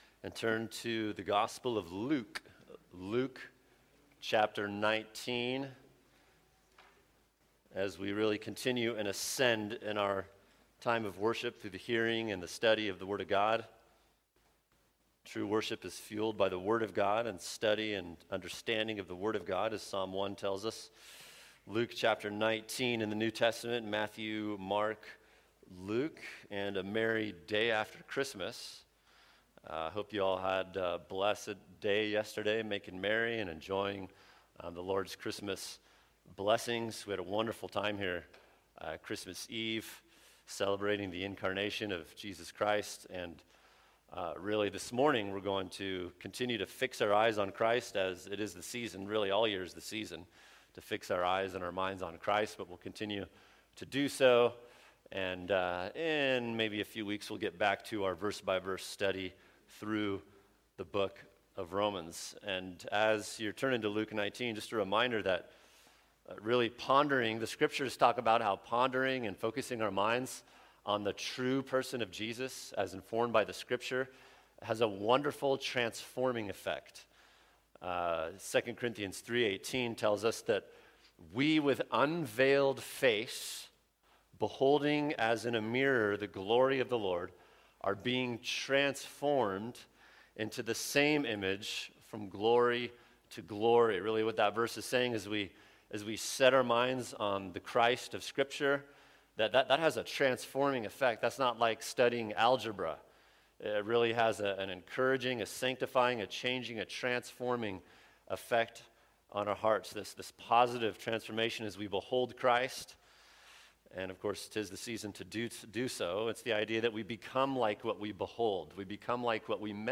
[sermon] Luke 19:1-10 The Seeking Savior | Cornerstone Church - Jackson Hole